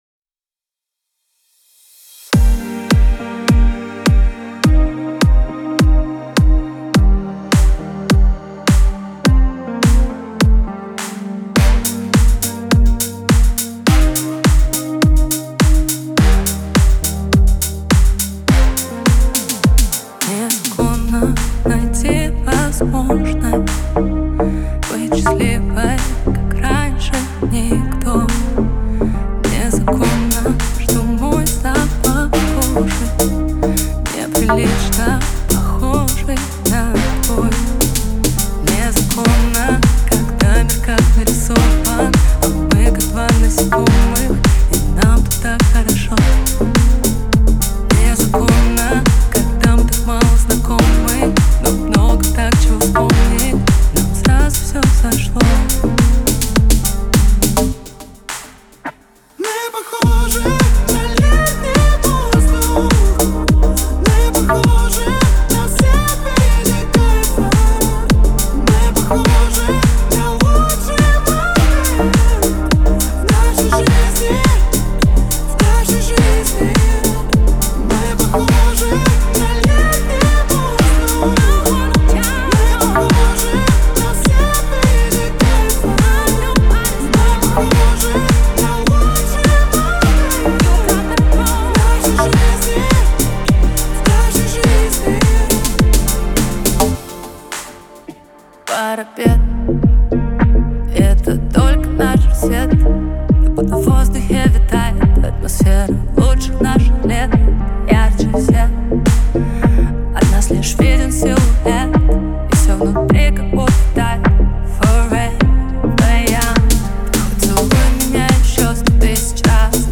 Клубная музыка
ремиксы
клубные песни